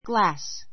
glass 中 A1 ɡlǽs グ ら ス ｜ ɡlɑ́ːs グ ら ー ス 名詞 複 glasses ɡlǽsiz グ ら セ ズ ❶ ガラス ✓ POINT 物質としてのガラスそのものを指す場合と, 「コップ」「眼鏡」などのようにガラスでできているものを指す場合がある. ❷ ❹ a glass window [box] a glass window [box] ガラス窓[の箱] Glass is easily broken.